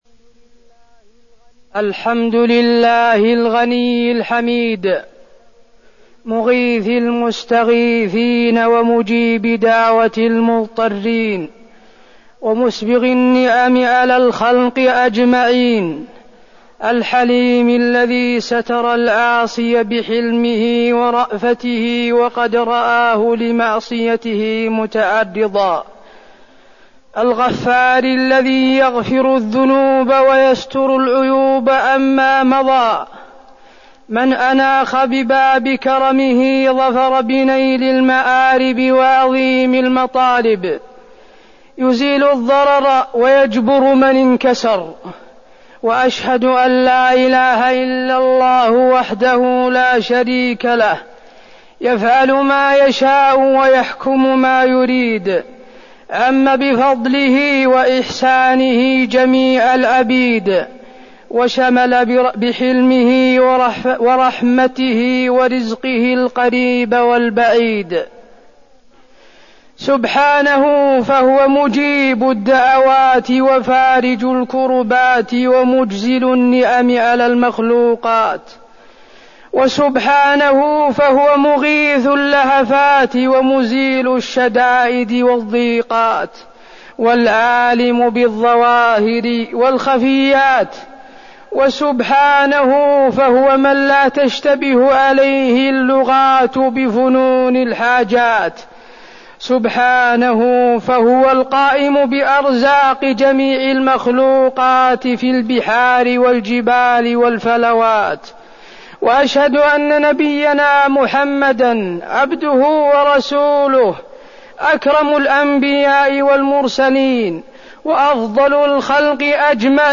خطبة الاستسقاء - المدينة- الشيخ حسين آل الشيخ
المكان: المسجد النبوي